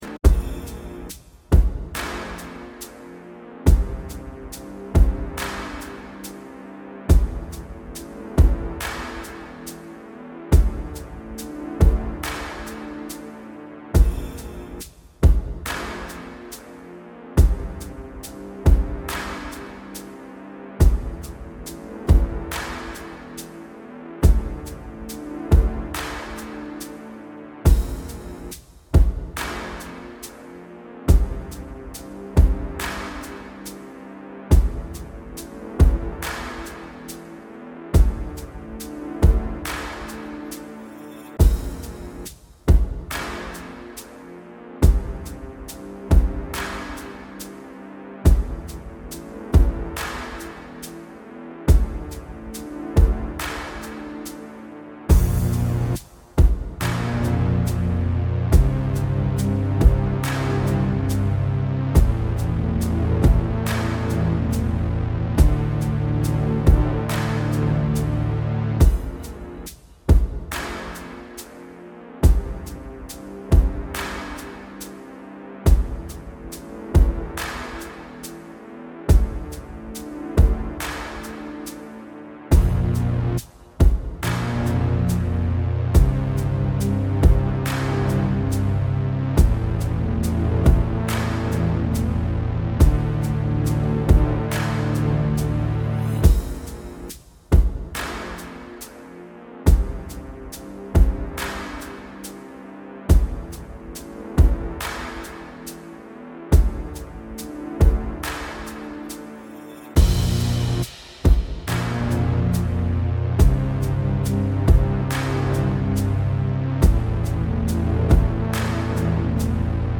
From Zero to Hero 0,5 Hz – 100 Hz Binaural Beats – Home
Binaural Beats sind ein akustisches Phänomen, das entsteht, wenn zwei Töne mit leicht unterschiedlichen Frequenzen auf jedes Ohr abgespielt werden.
Ambient Brain Boot Beat 0,1 Hz - 100 Hz.mp3